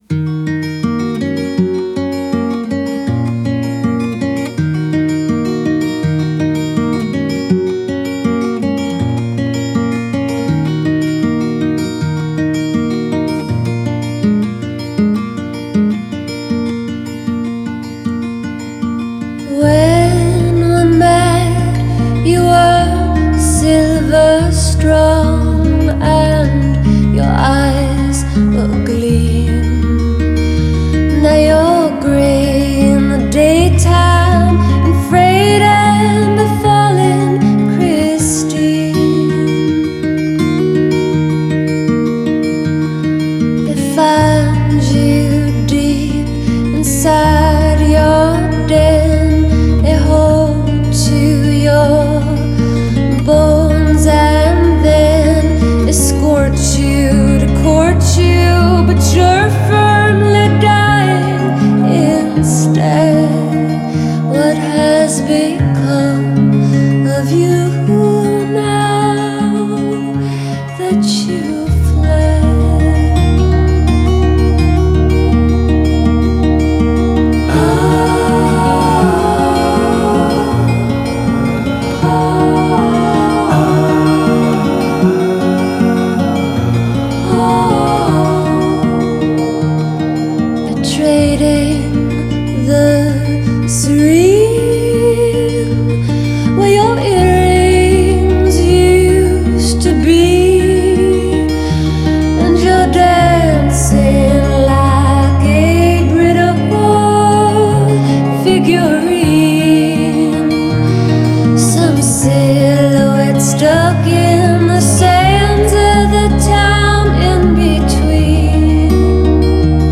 Style: Indie Folk